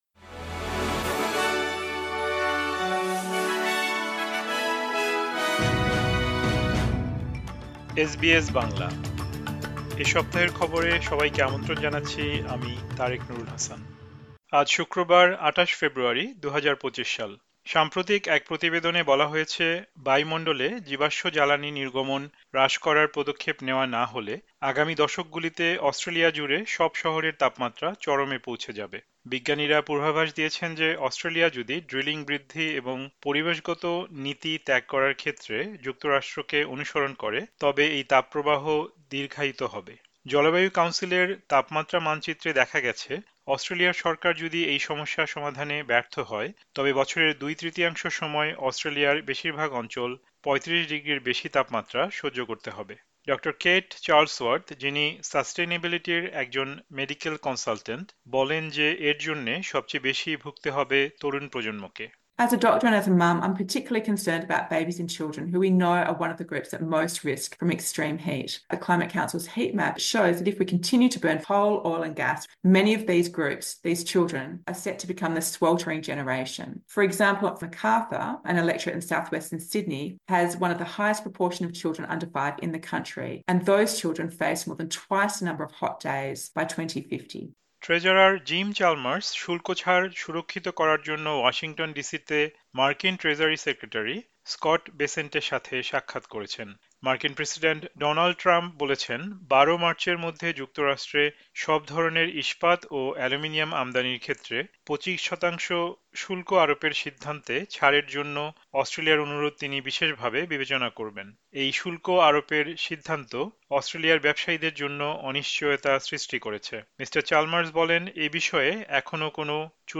এ সপ্তাহের খবর: ২৮ ফেব্রুয়ারি, ২০২৫